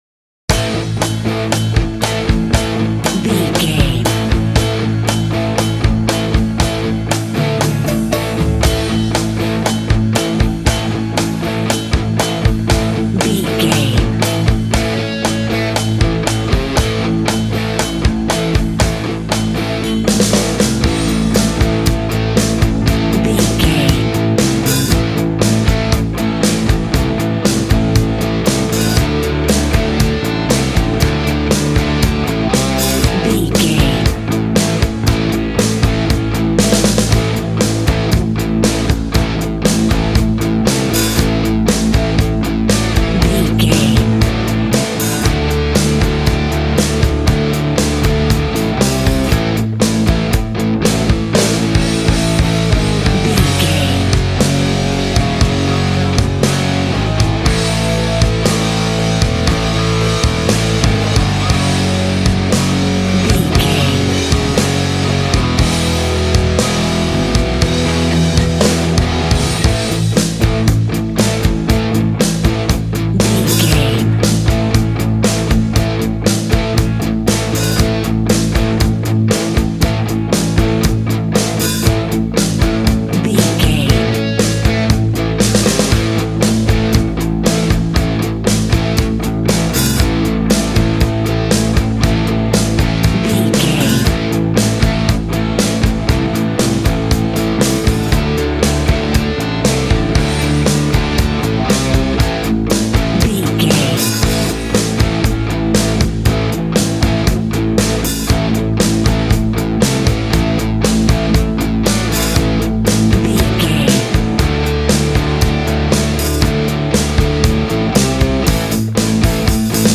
Ionian/Major
groovy
powerful
electric guitar
bass guitar
drums
organ